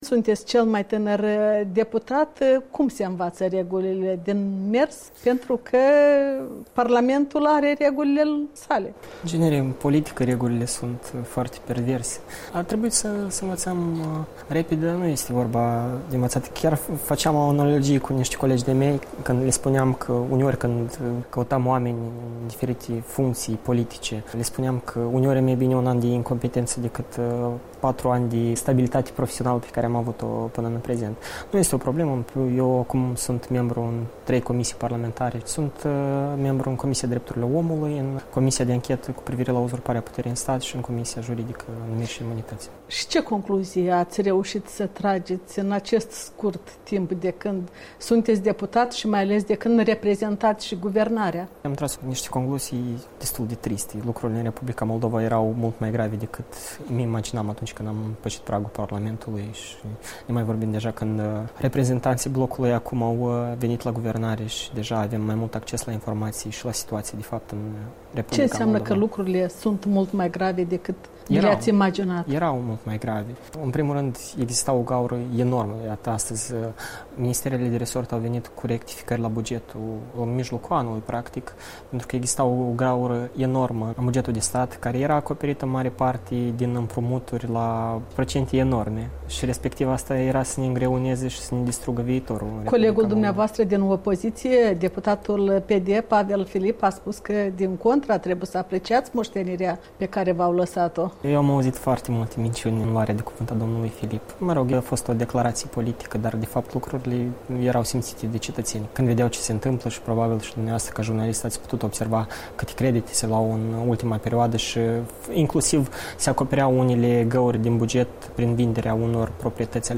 Un interviu cu deputatul cel mai tânăr din noul Parlament, președinte al Organizației de Tineret, Platforma DA.